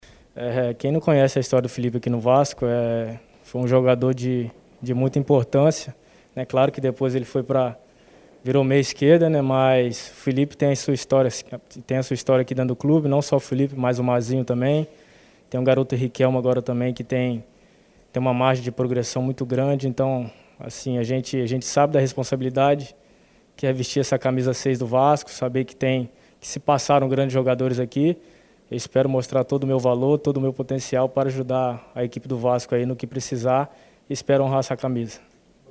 Entrevista coletiva